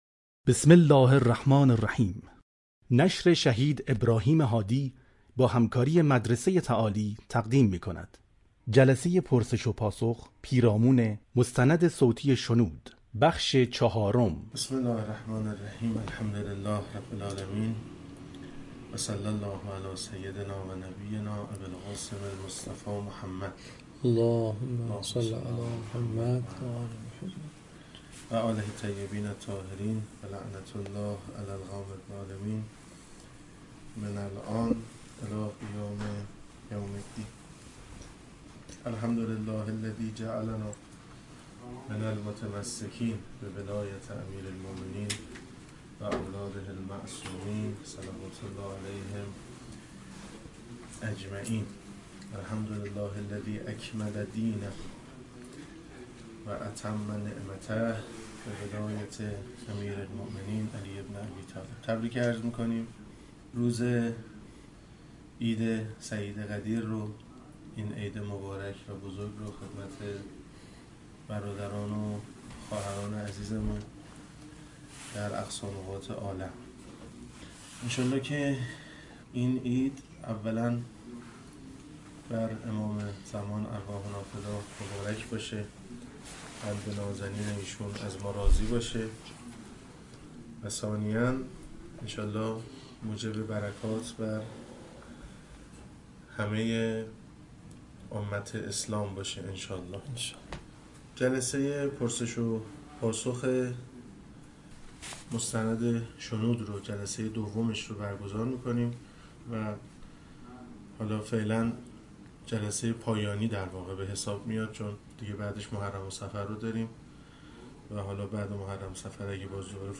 مستند صوتی شنود - جلسه 20 (بیستم) / پرسش و پاسخ (4)